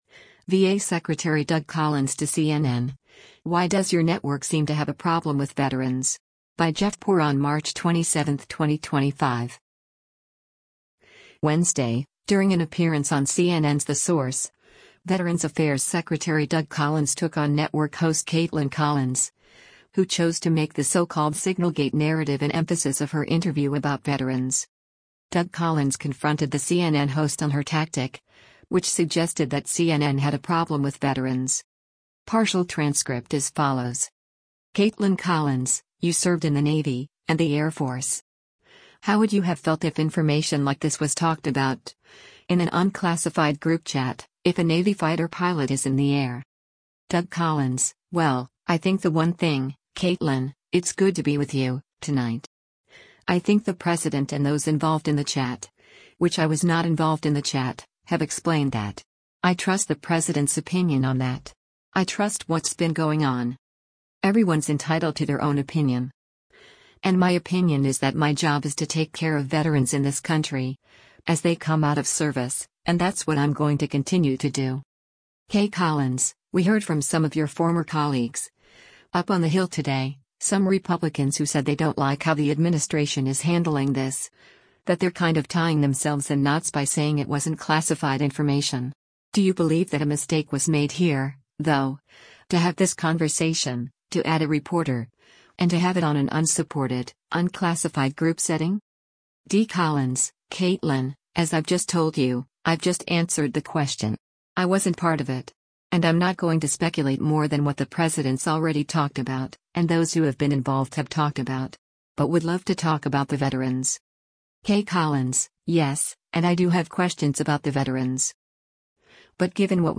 Wednesday, during an appearance on CNN’s “The Source,” Veterans Affairs Secretary Doug Collins took on network host Kaitlan Collins, who chose to make the so-called Signalgate narrative an emphasis of her interview about veterans.